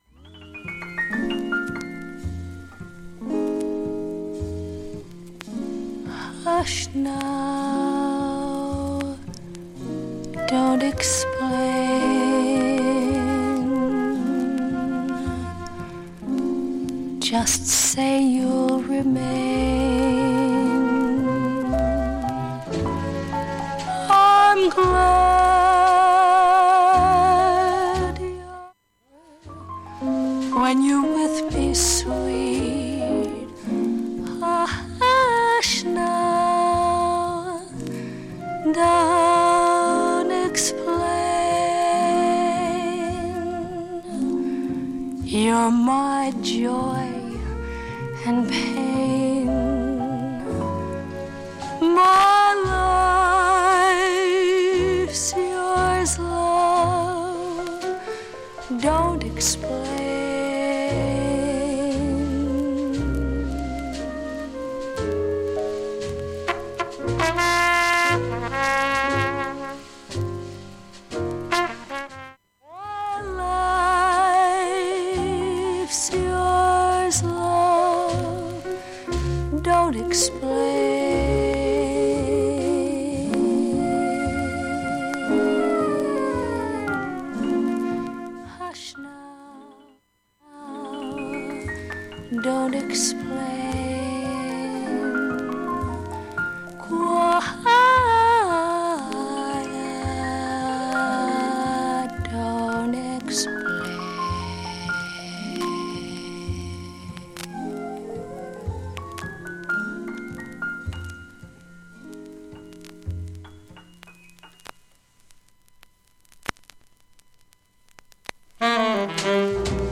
現物の試聴（上記）できます。音質目安にどうぞ
shure44gステレオ針での試聴です。
A面はジりパチ感結構感じますが、
ピーキーな音割れはありませんでした。